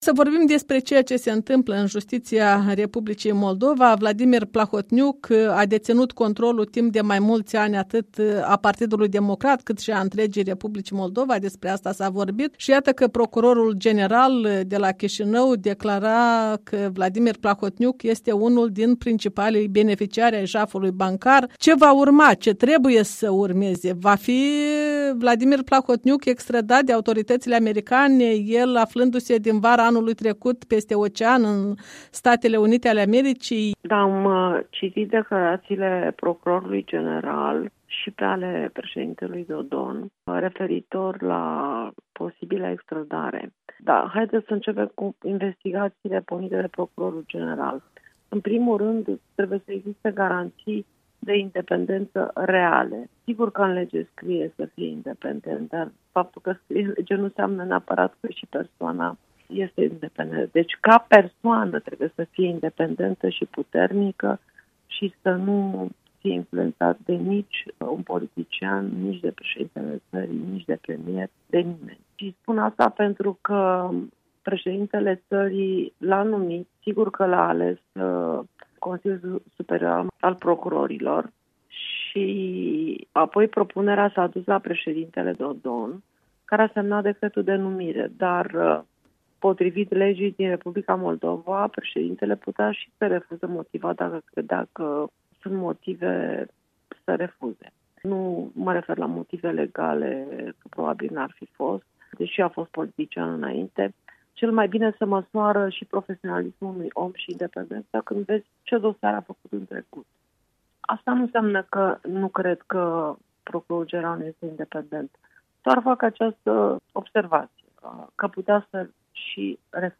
Interviu cu Monica Macovei